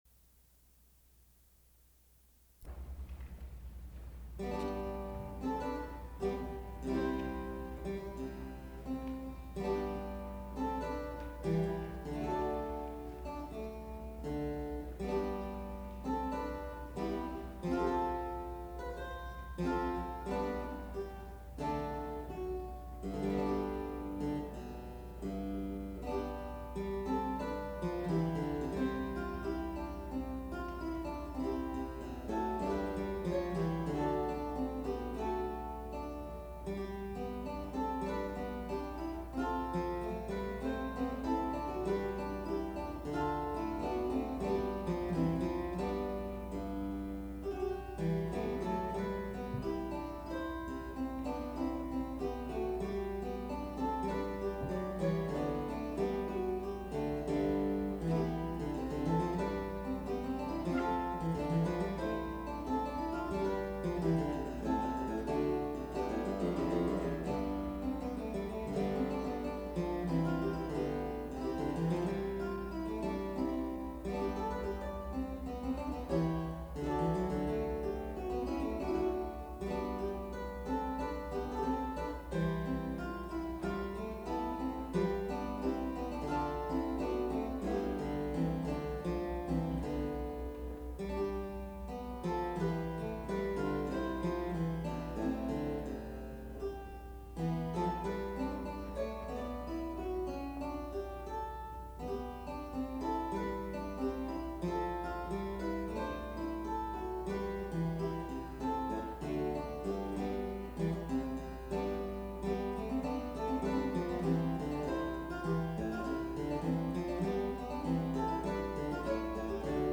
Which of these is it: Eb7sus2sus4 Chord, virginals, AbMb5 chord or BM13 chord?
virginals